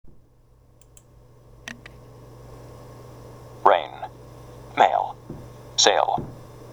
[エイ] rain, mail, sale